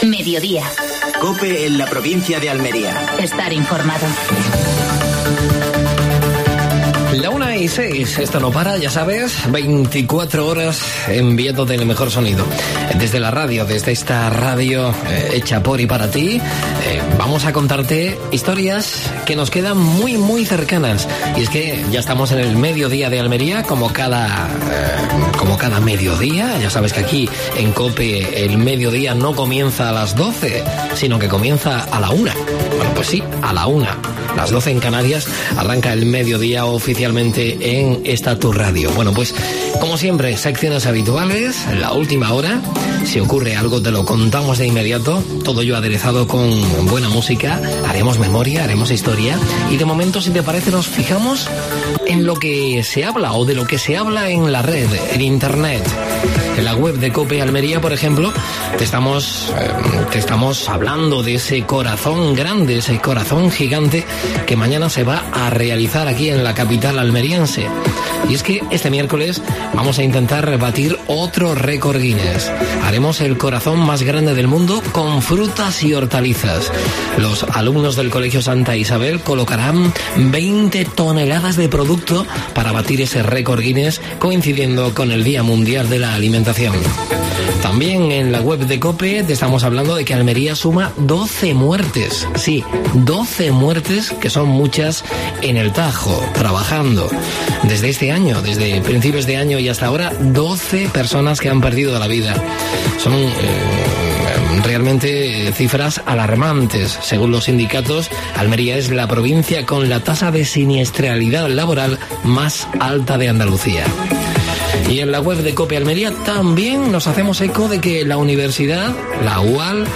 AUDIO: Actualidad en Almería. Entrevista